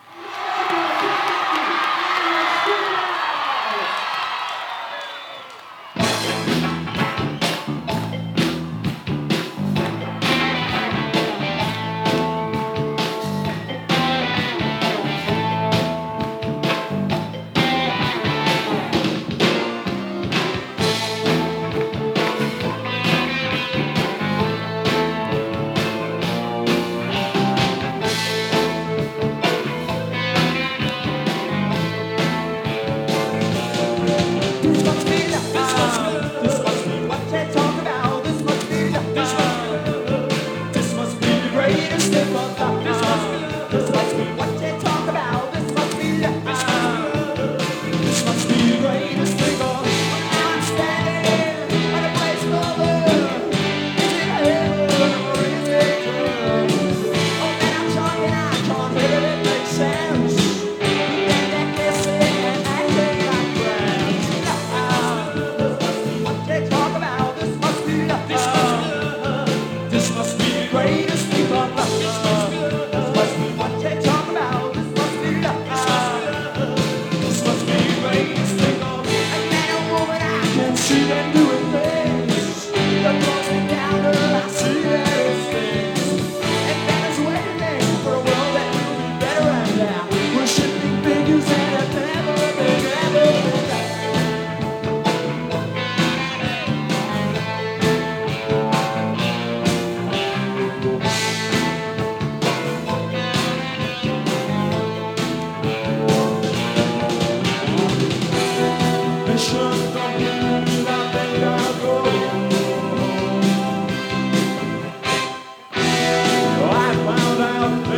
哀愁ネオスカ・フレイヴァで幻想的なインディー・ポップ！